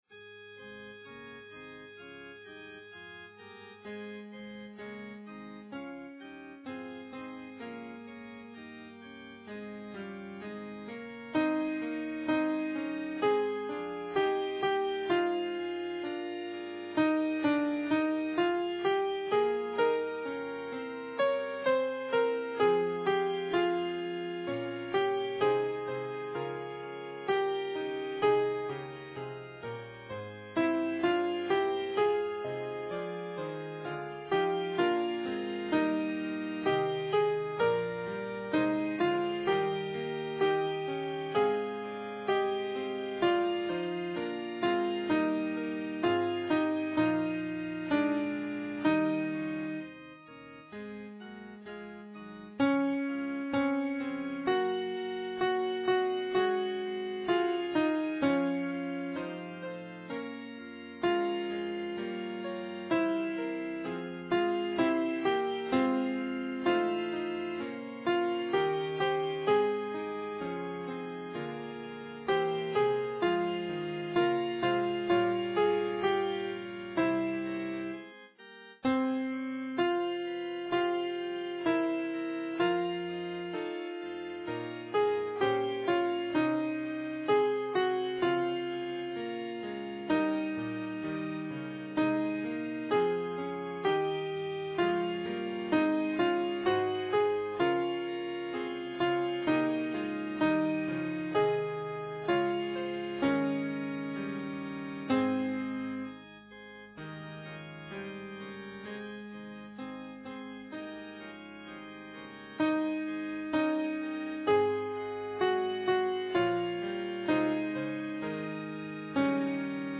Les fichiers de travail mettent en relief au piano chacune des voix dans le contexte général ; ce sont des fichiers .mp3 qui peuvent être lus par un ordinateur, un lecteur mp3, ou directement gravés sur un CD audio.
Lorsque le choix en a été possible, le tempo des fichiers de travail est bien entendu un tempo de travail, et ne reflète pas nécessairement celui de notre interprétation finale...